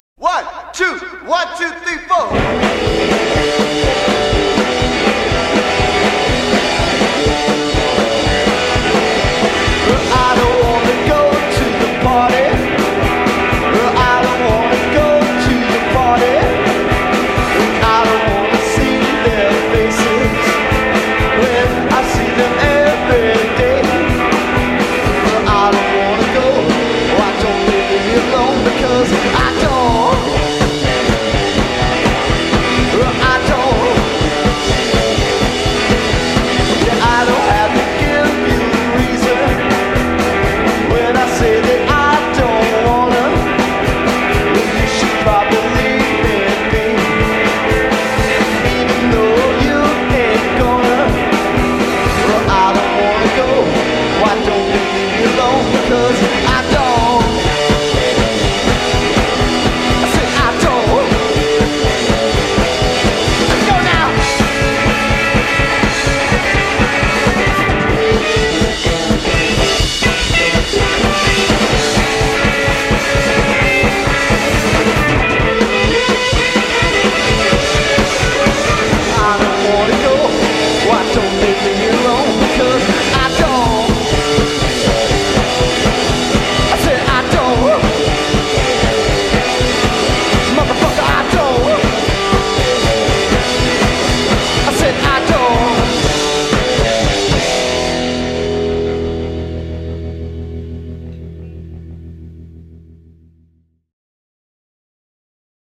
extremely addictive